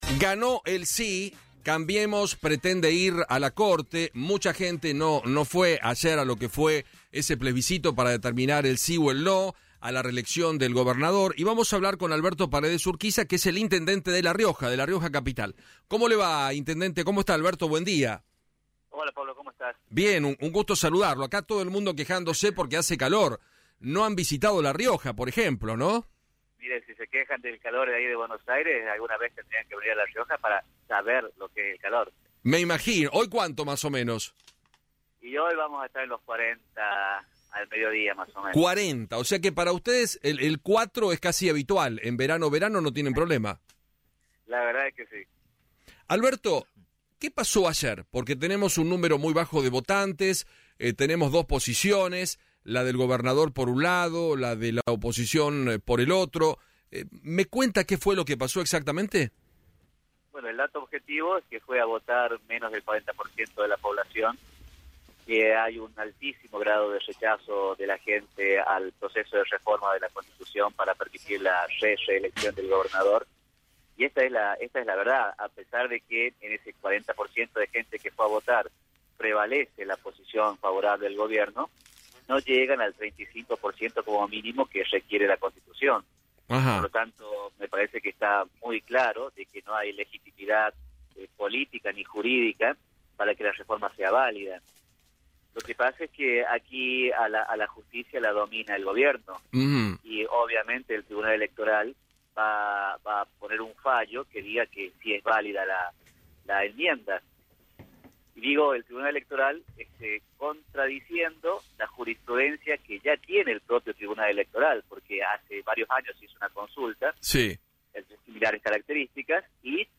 Alberto Paredes Urquiza, Intendente de la Ciudad de La Rioja, habló en Feinmann 910